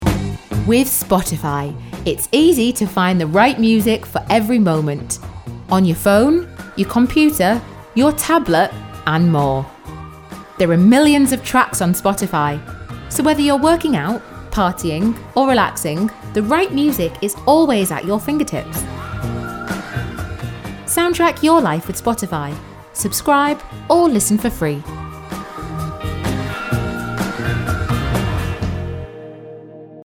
Never any Artificial Voices used, unlike other sites.
Female
English (British)
Yng Adult (18-29), Adult (30-50)
Radio Commercials
Commercial Spotify Advert
All our voice actors have professional broadcast quality recording studios.